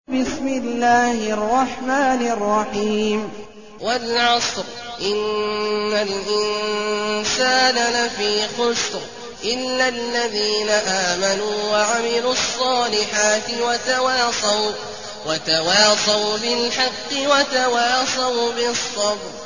المكان: المسجد النبوي الشيخ: فضيلة الشيخ عبدالله الجهني فضيلة الشيخ عبدالله الجهني العصر